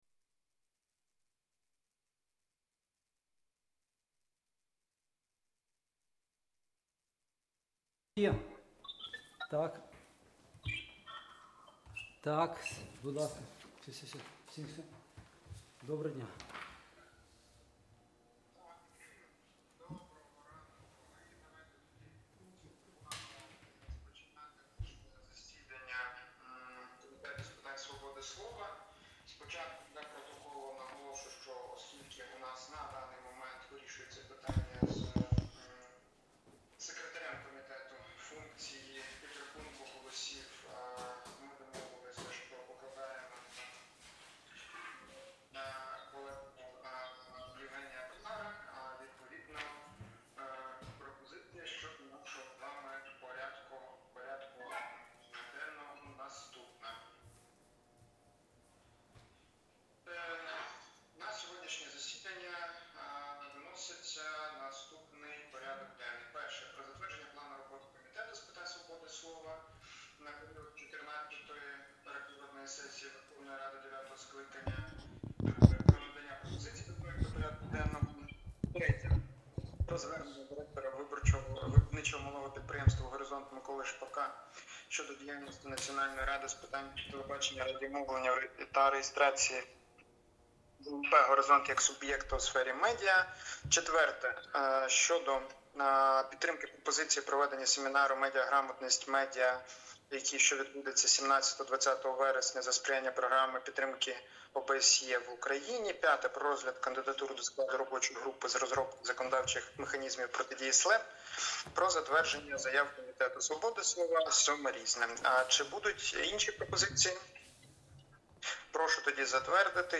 Аудіозапис засідання Комітету від 29 липня 2025р.